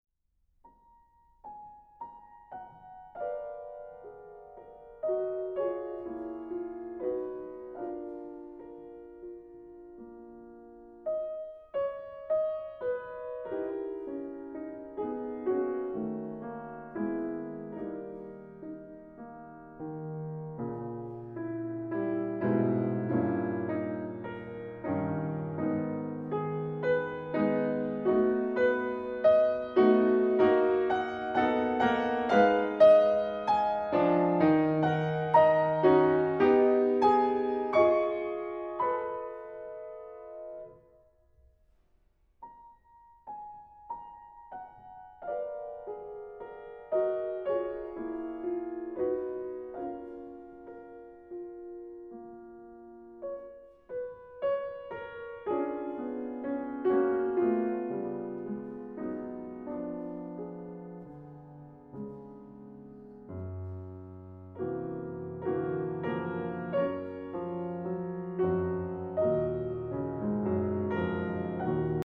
Žanrs: Klaviermūzika
Instrumentācija: klavierēm